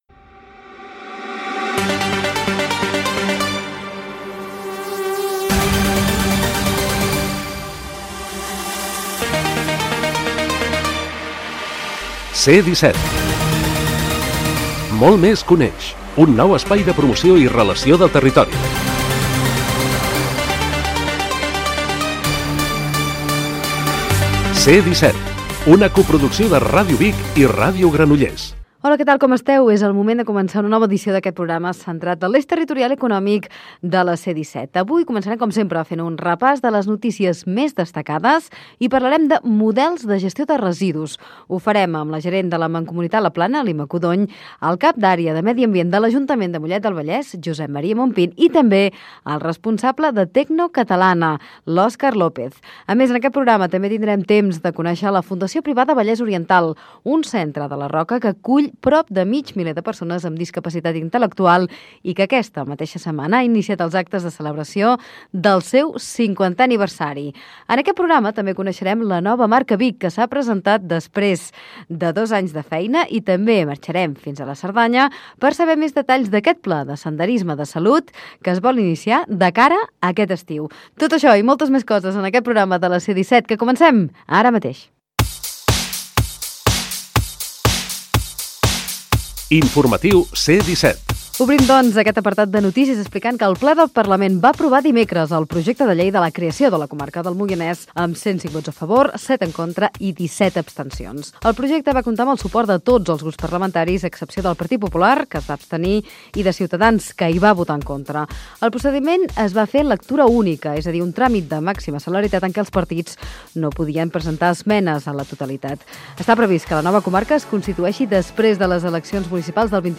Careta, presentació, sumari i repàs informatiu .
Informatiu